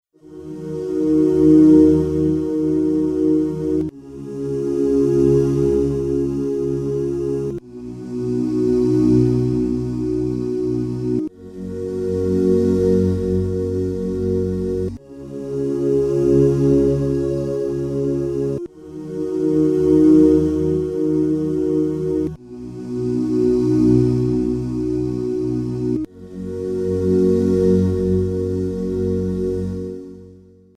Disk SL-516 "Ooos" choir